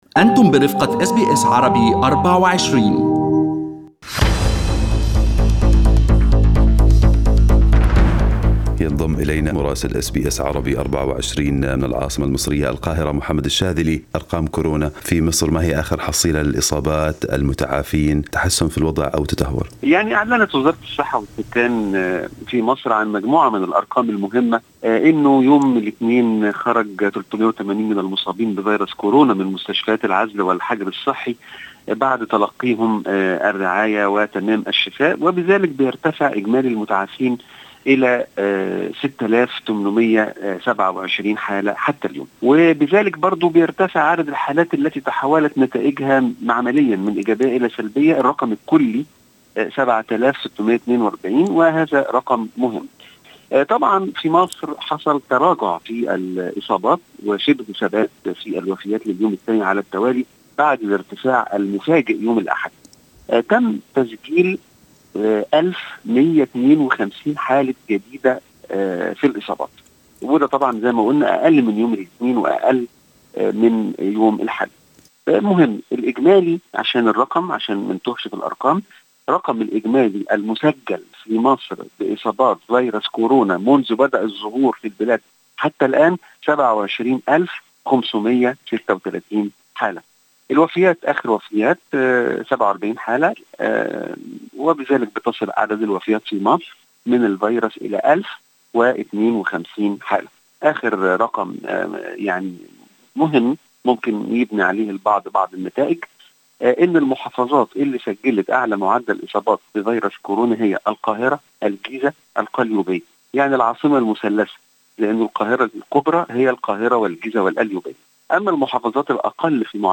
من مراسلينا: أخبار مصر في أسبوع 03/06/2020